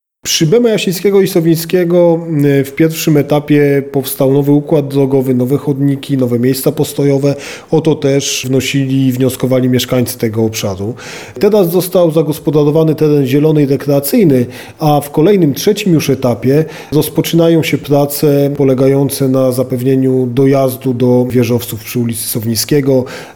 Mówi wiceprezydent miasta, Mateusz Tyczyński: